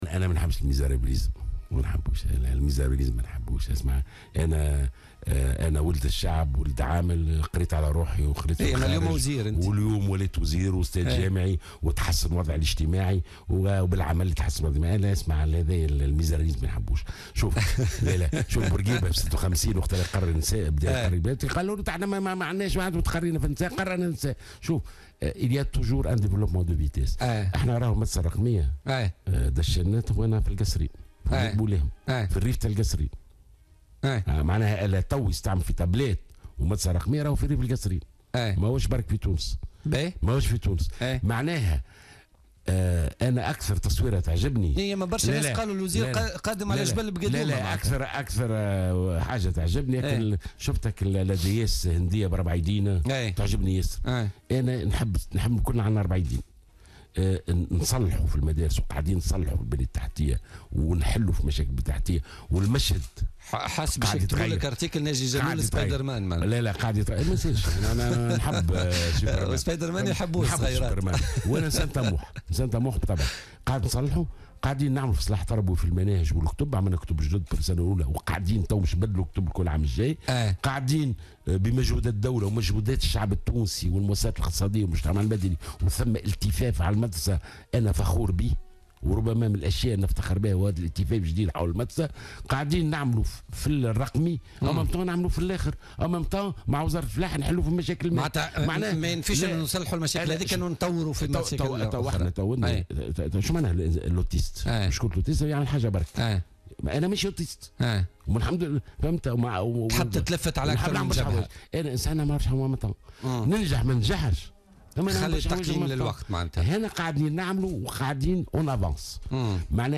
أعلن وزير التربية ناجي جلول ضيف برنامج بوليتكا لليوم الجمعة 11 نوفمبر 2016 أنه سيتم تعميم مشروع "المدرسة الرقمية" في كل المدارس الابتدائية في الجمهورية.